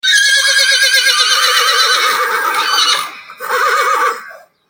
Hiii, hiii
CABALLO (audio/mpeg)